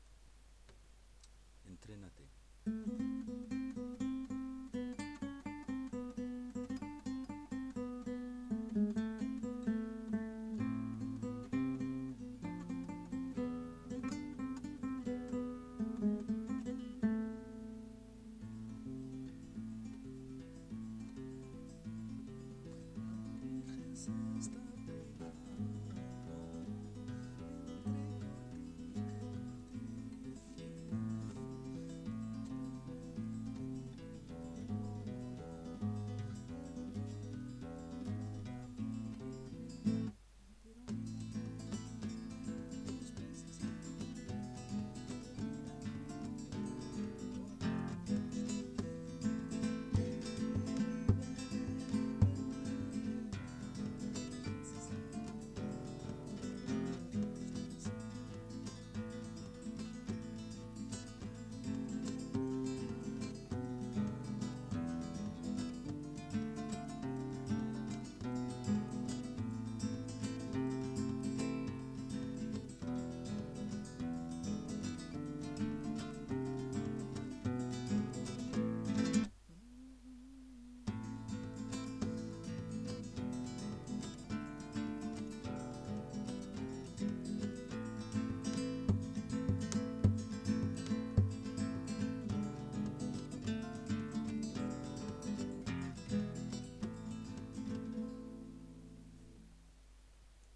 En classe, nous écouterons "un villancico muy flamenco" avec les couplets ci-dessous.
naci'o : prononciation andalouse du participe passé. nacido.
cansa'a : prononciation andalouse du participe passé.